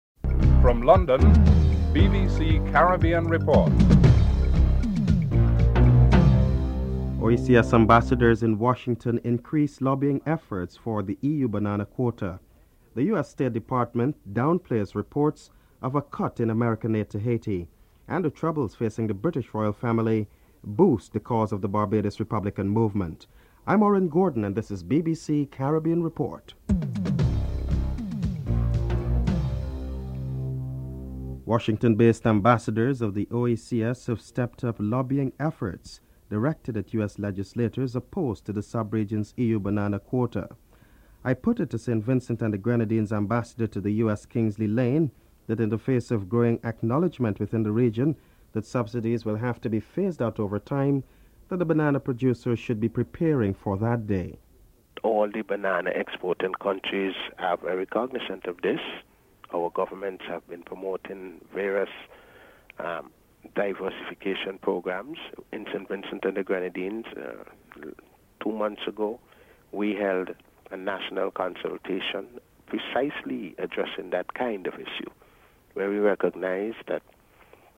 2. OECS ambassadors in Washington increase lobbying efforts for the EU banana quota. St. Vincent and the Grenadines Ambassador to the United States Kingsley Layne is interviewed 00:32-03:22)
A US State Department spokesman Glyn Davies is interviewed (03:23-07:25)